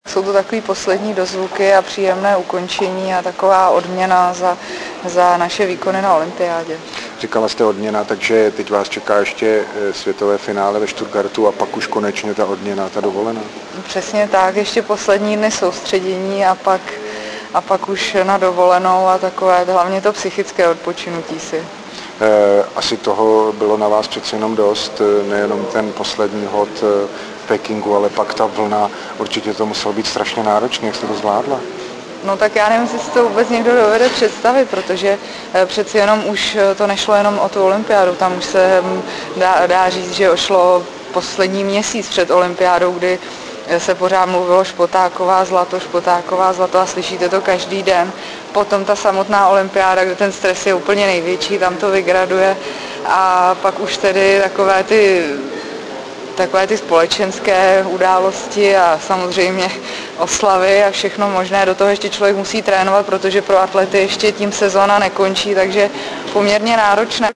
Rozhovor M. Topolánka s B. Špotákovou